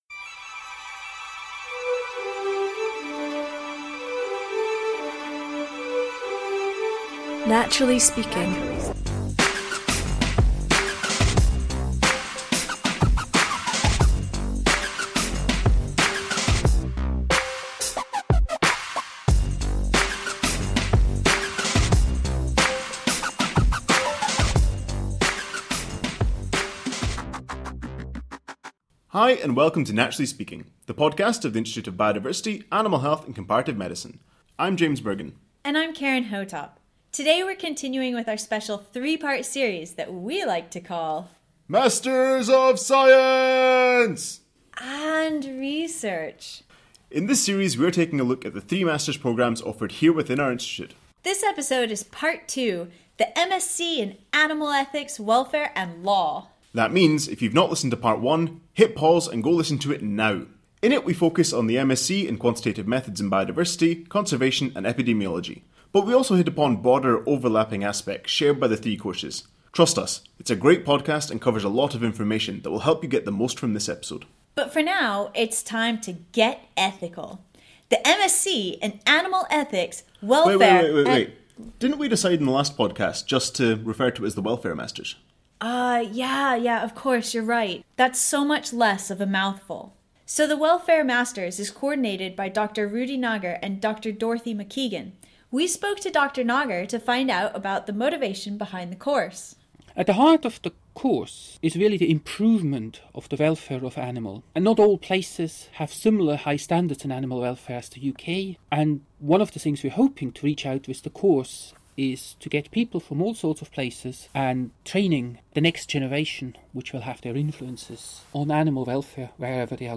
Get ready for a diverse crew, with accents from across Europe and North America, and projects ranging from the impact of assisted births on dairy calves to chimpanzee rehabilitation.